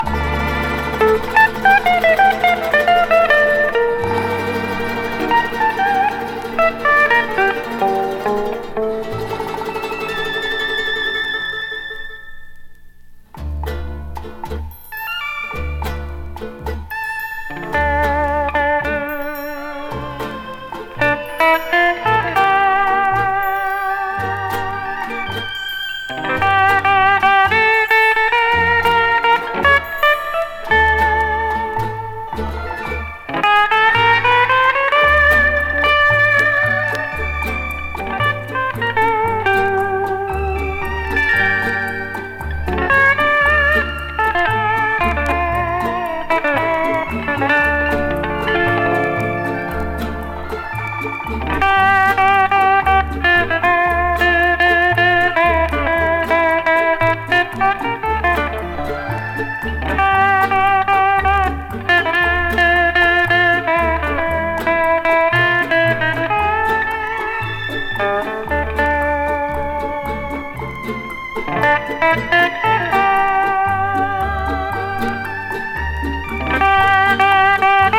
夏にピッタリ。